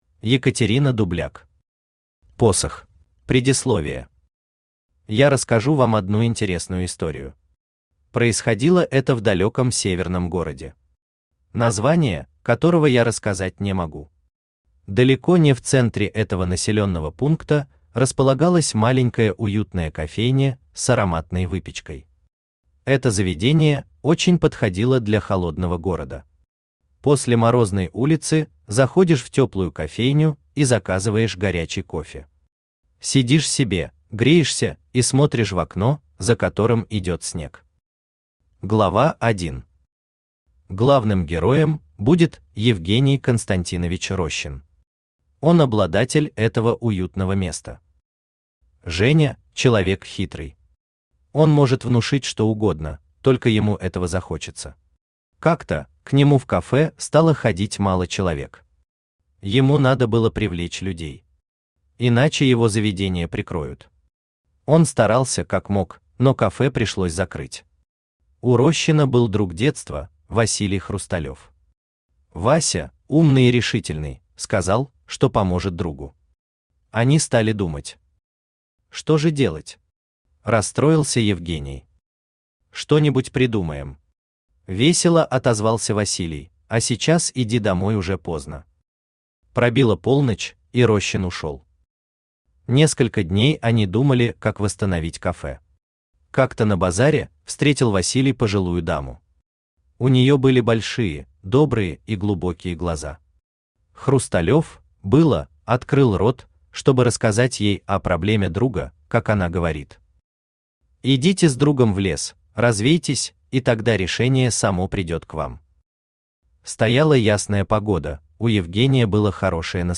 Аудиокнига Посох | Библиотека аудиокниг
Aудиокнига Посох Автор Екатерина Дубляк Читает аудиокнигу Авточтец ЛитРес.